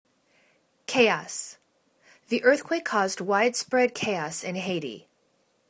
cha.os     /ka:os/    n